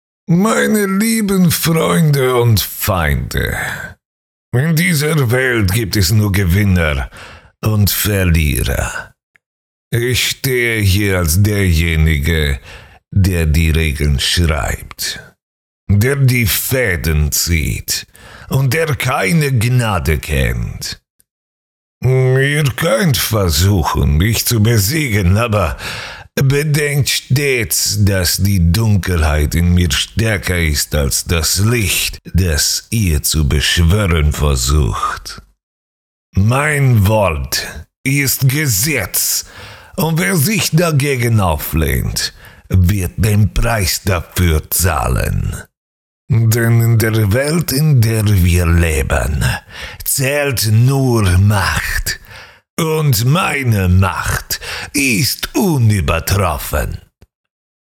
Sprecher, Synchronsprecher